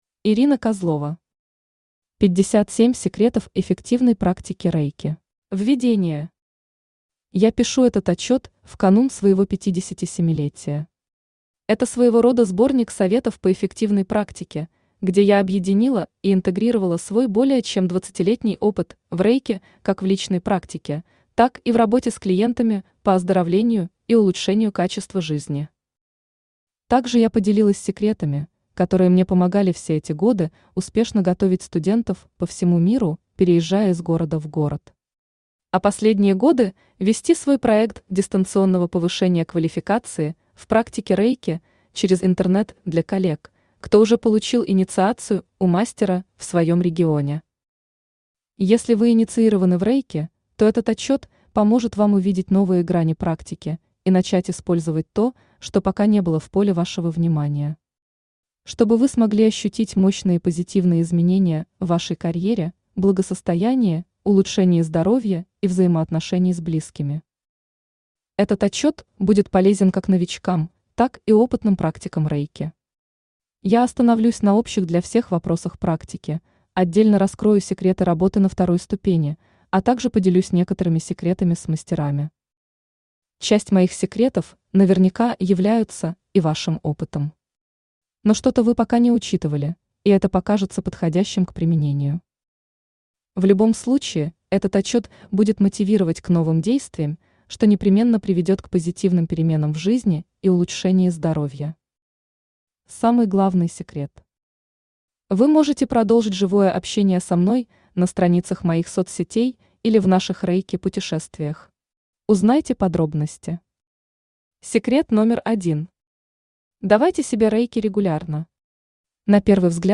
Aудиокнига 57 секретов эффективной практики Рэйки Автор Ирина Александровна Козлова Читает аудиокнигу Авточтец ЛитРес.